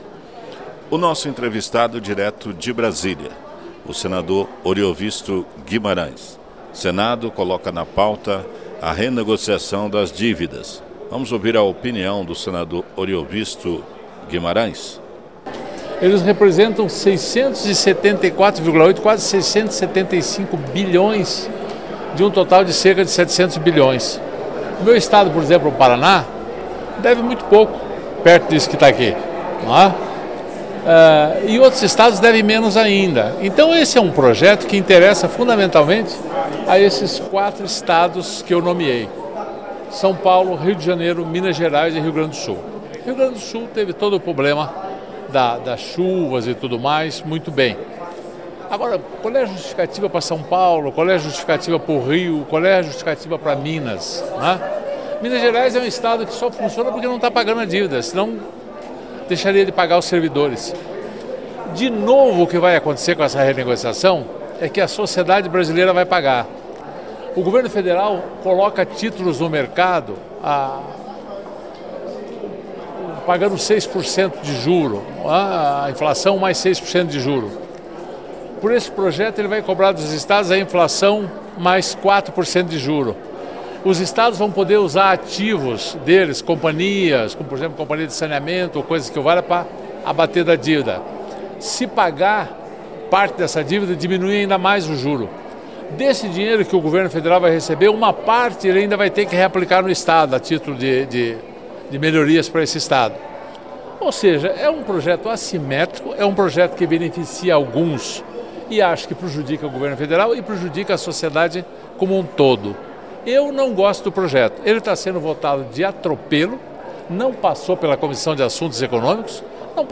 Oriovisto Guimarães conversou com o jornalista Carlos Nascimento.